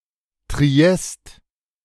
1. ^ Austrian German: Triest [triˈɛst]
De-Triest.ogg.mp3